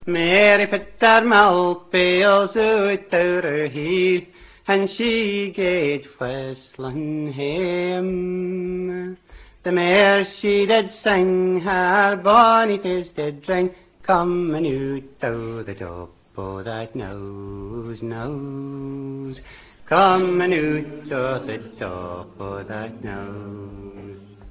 Ballads of true and false lovers
play Sound Clipis that he immerses himself so totally in what he is doing that the story/song takes over and he seems to become a mere vehicle of delivery.